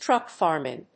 アクセントtrúck fàrming